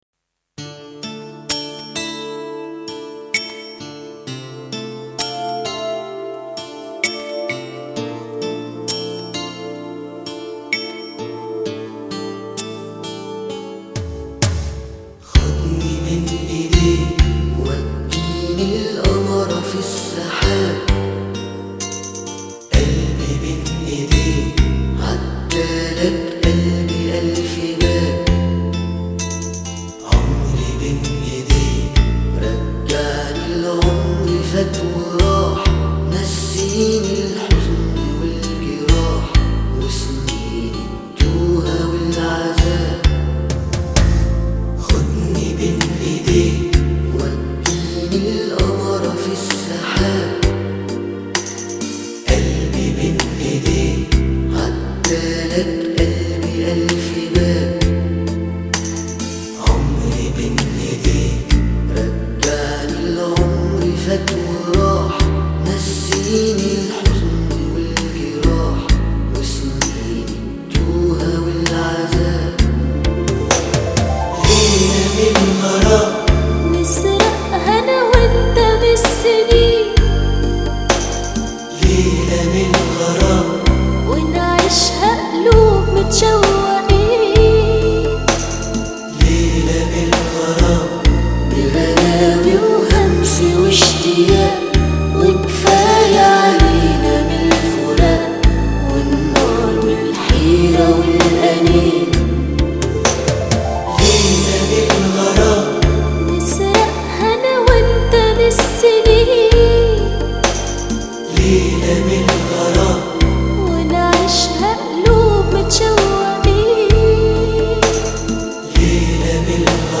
这是一首阿拉伯语种歌曲。前半部分的男和声是一望无垠的沙漠， 后半部分清亮的女声加入,如同沙漠尽头处忽现的一片绿洲。